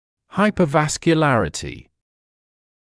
[ˌhaɪpəˌvæskjə’lærətɪ][ˌхайпэˌвэскйэ’лэрэти]гиперваскулярность